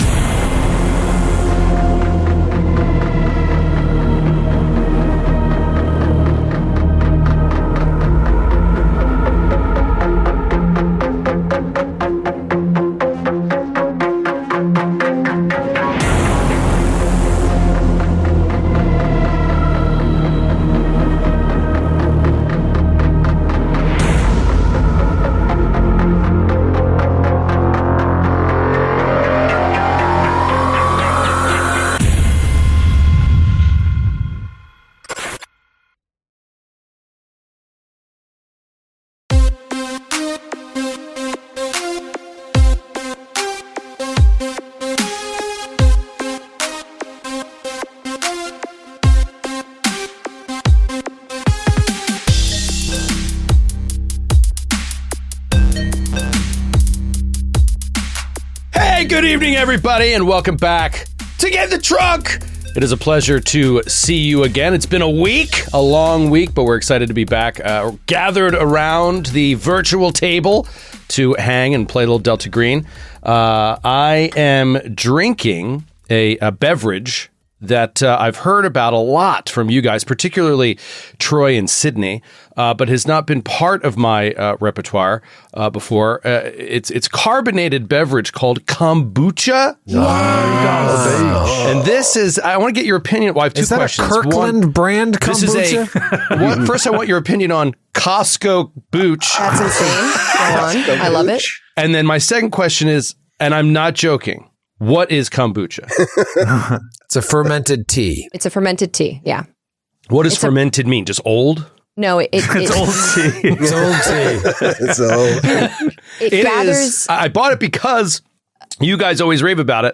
Get in the Trunk is an anthology series playing through various Delta Green scenarios by Arc Dream Publishing, such as Last Things Last, The Last Equation, A Victim of the Art and Ex Oblivione.